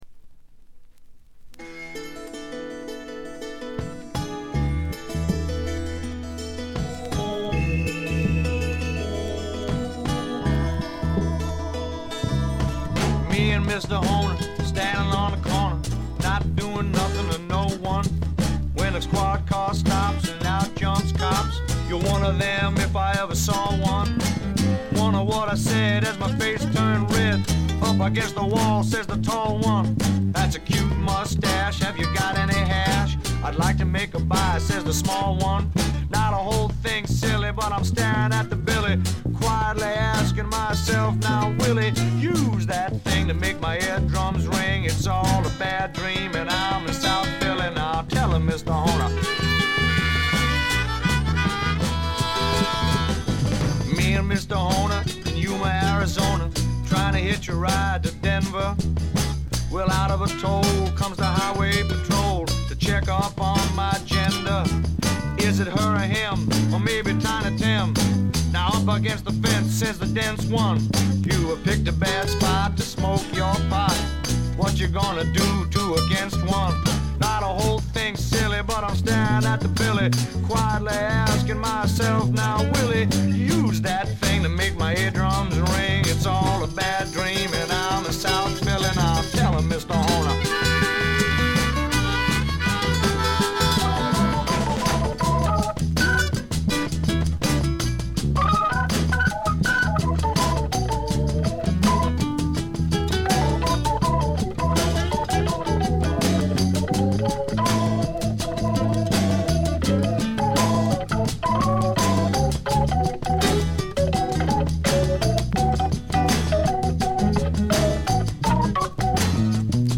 ほとんどノイズ感無し。
けっこう色々な音が鳴っていて厚みがあるのと音そのものがすごくいいコードです。
試聴曲は現品からの取り込み音源です。